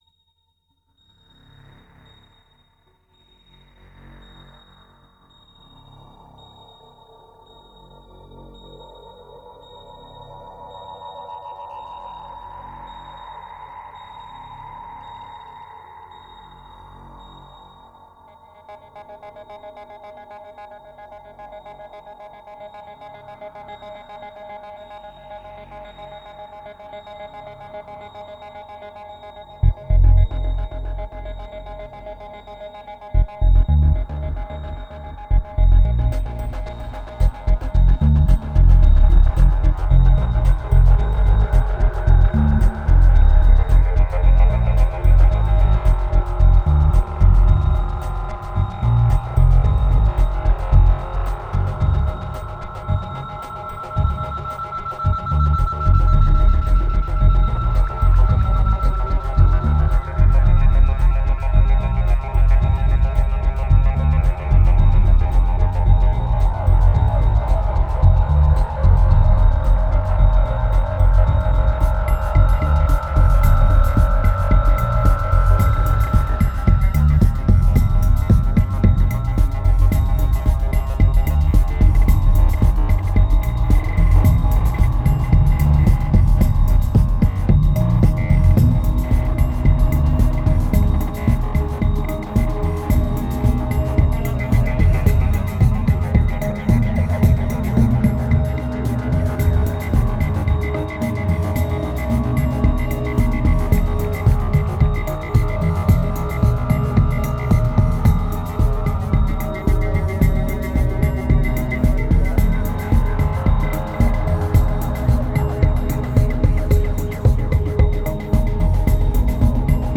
2120📈 - -59%🤔 - 111BPM🔊 - 2010-09-23📅 - -311🌟